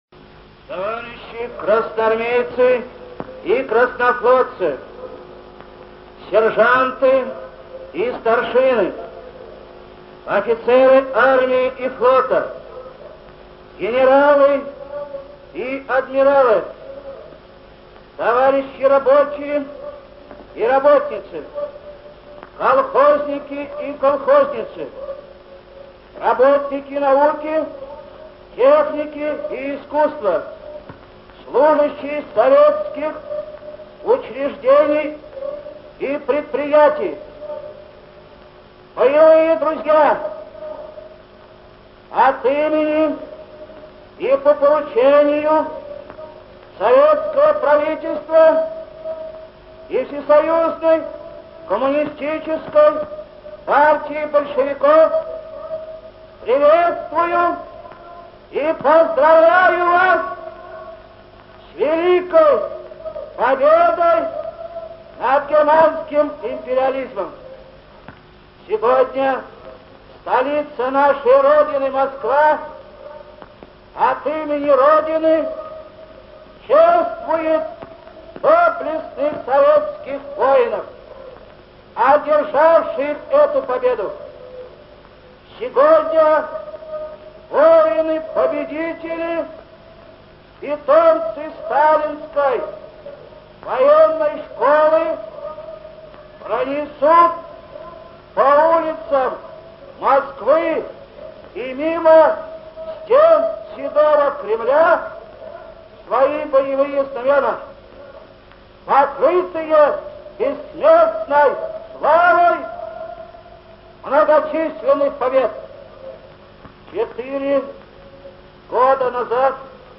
Речь Г.К.Жукова на Параде Победы (без купюр)
Жуков.Речь на Параде Победы 1945г.mp3